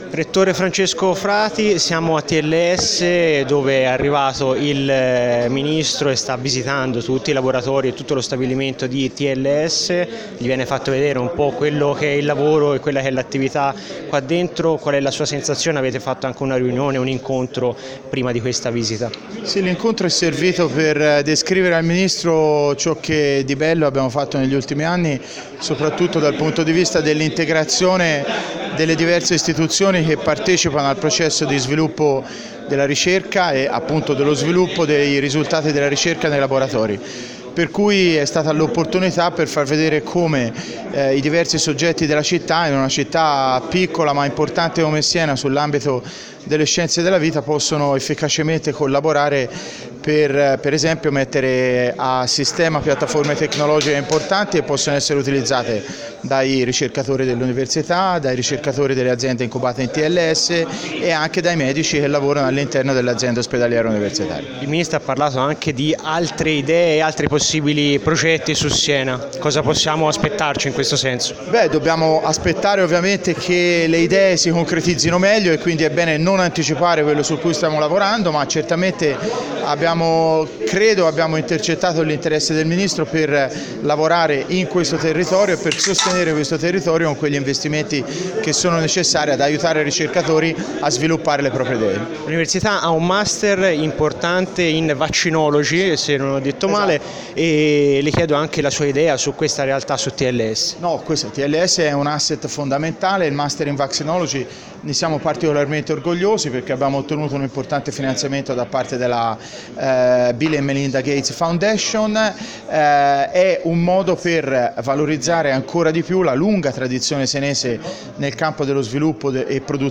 Ascolta l’intervista al Rettore dell’Università di Siena Francesco Frati